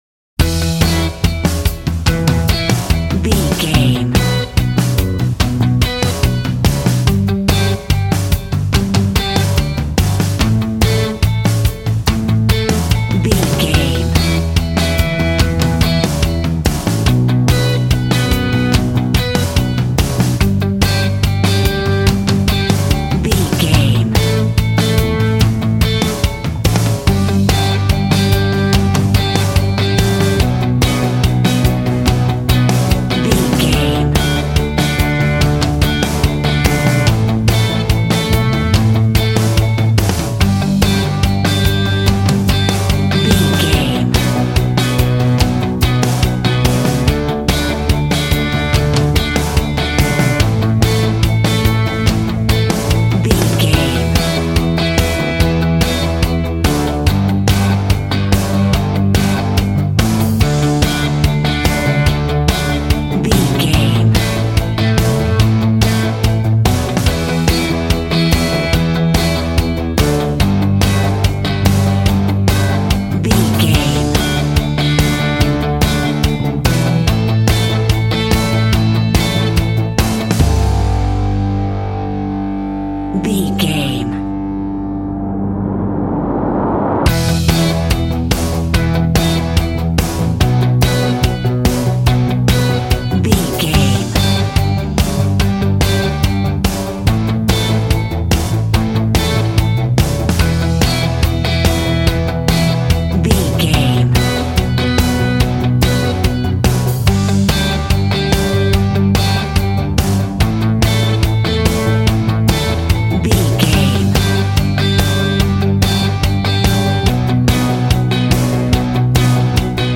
Aeolian/Minor
groovy
powerful
organ
drums
bass guitar
electric guitar
piano